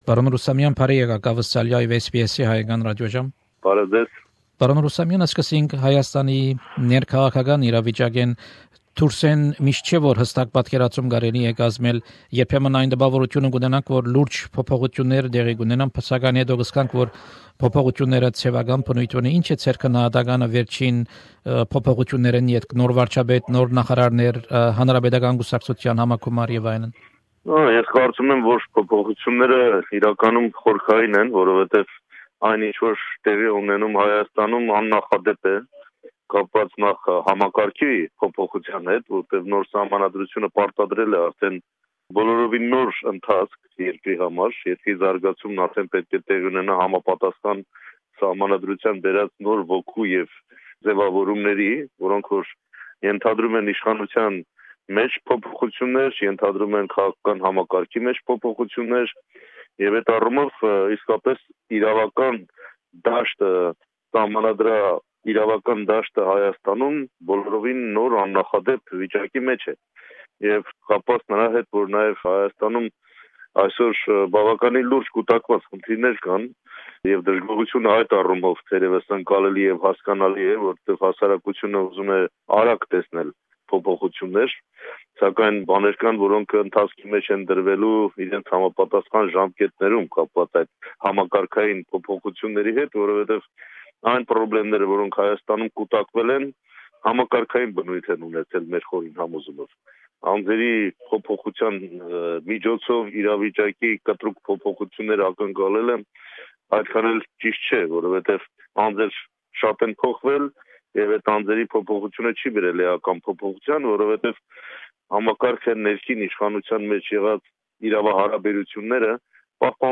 Interview with Armen Rustamyan, Head of ARF faction in the Armenian National Assembly. Mr Rustamyan is currently visiting Australia. (Full interview)